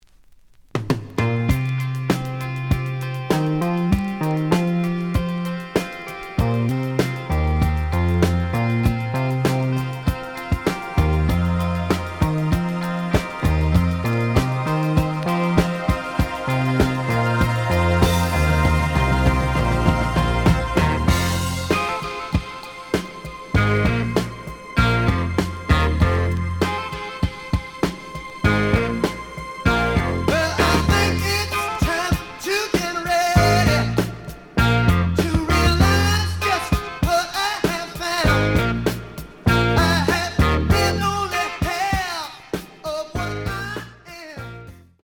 試聴は実際のレコードから録音しています。
The audio sample is recorded from the actual item.
●Genre: Rock / Pop